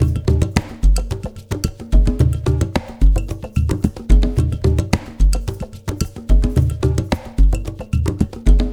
APH ETHNO2-R.wav